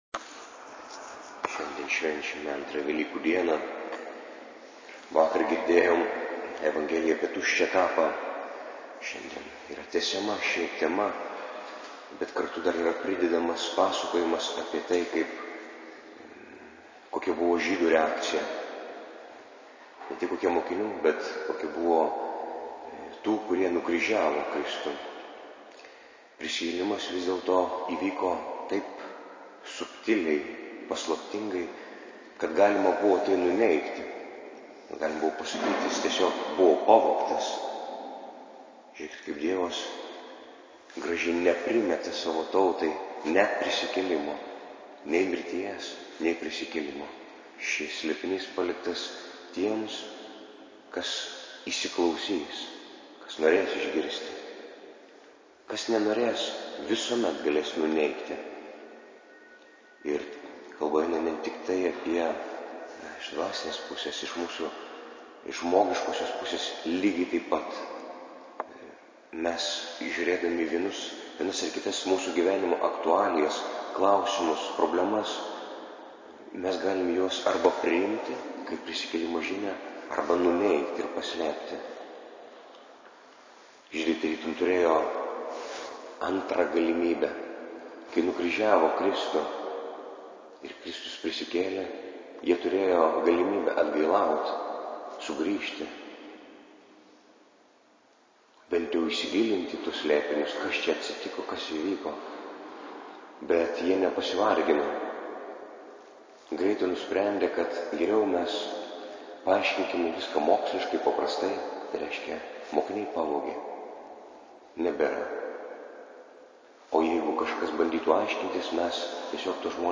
Audio Pamoksas: